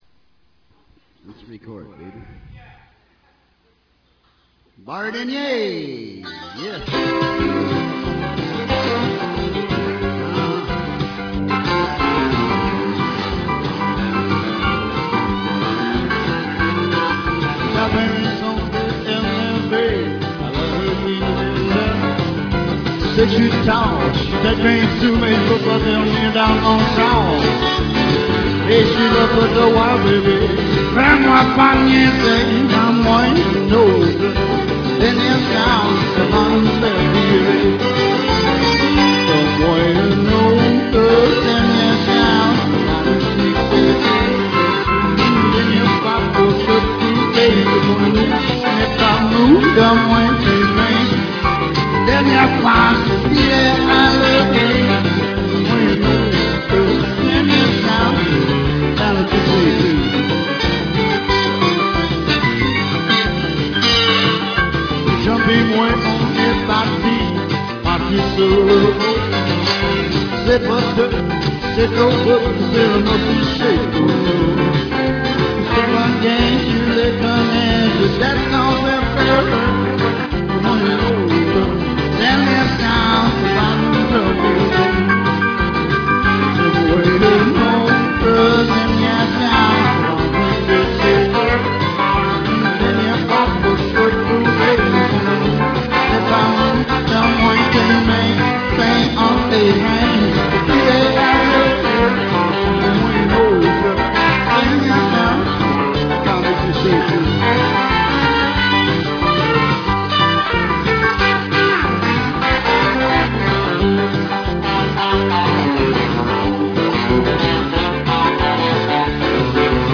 * * *Country Rock* * * live guitar and vocals